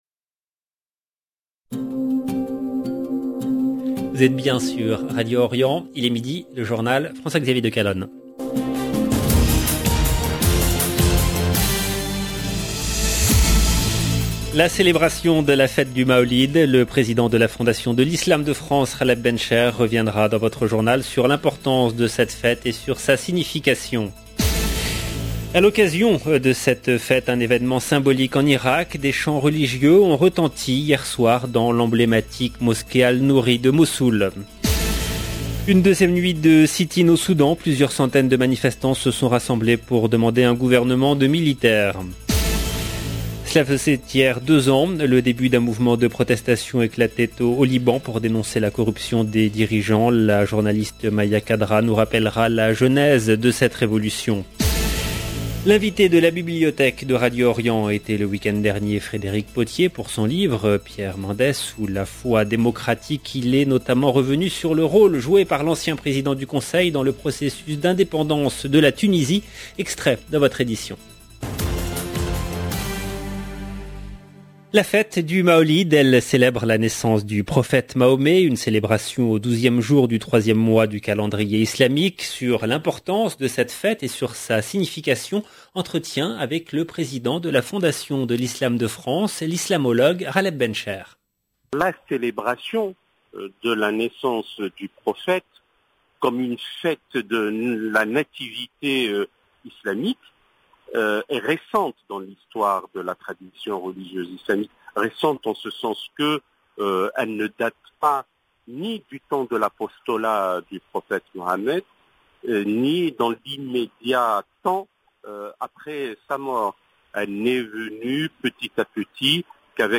Liban islam 18 octobre 2021 - 17 min 36 sec Le journal de midi en langue française du 18/10/21 LB JOURNAL EN LANGUE FRANÇAISE La célébration de la fête du Mawlid.